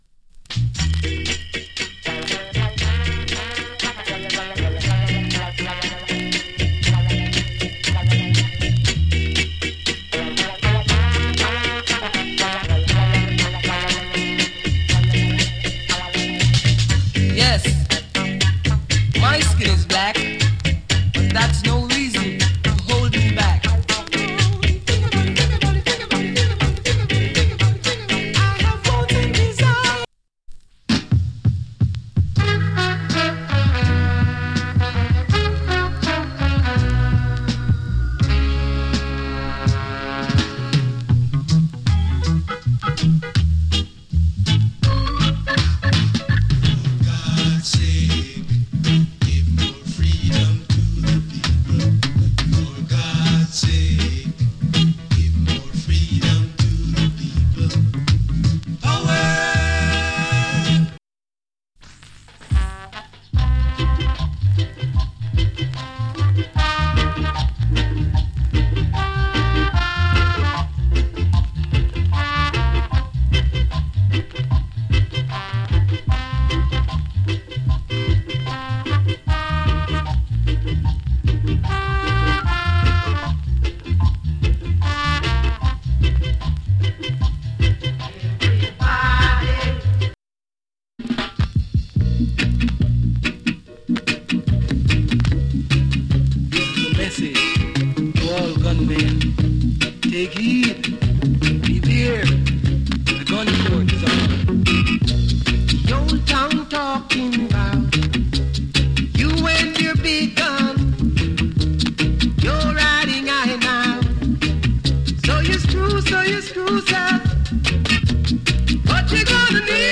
わずかにチリ、ジリノイズ有り。
70年代初期の EARLY REGGAE 〜 REGGAE 期の音源集 !!